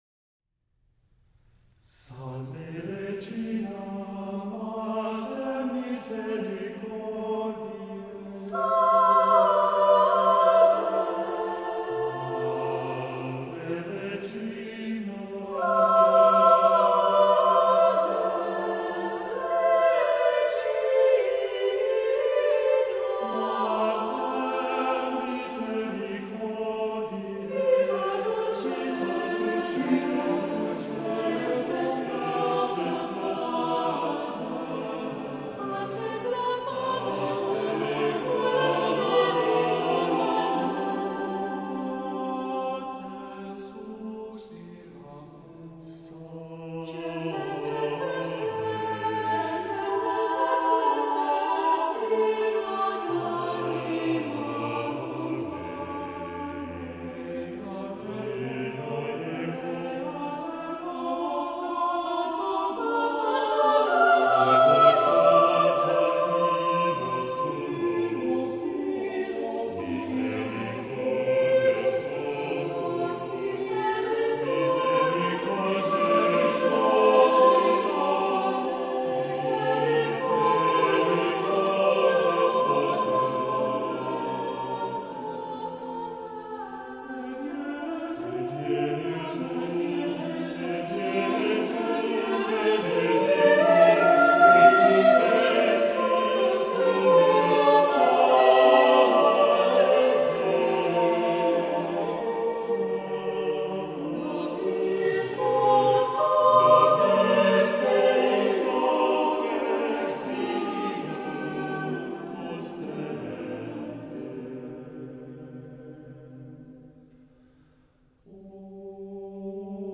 SATB a cappella (c. 4:15)